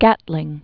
(gătlĭng), Richard Jordan 1818-1903.